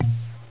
tuning forks
To illustrate effects of material damping (a manifestation of behavior variously called viscoelasticity, anelastic behavior, internal friction, hysteresis), listen to the sound of tuning forks with different damping. Each fork is identical in size and shape, but the material stiffness and density are different, giving rise to differences in pitch.
Polystyrene, tan delta = 0.02 inferred from the decay of vibration.